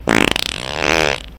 perfect-fart
explosion fart flatulation gas nascar poot sound effect free sound royalty free Memes
Short, definite fart